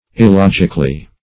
Il*log"ic*al*ly, adv. -- Il*log"ic*al*ness, n.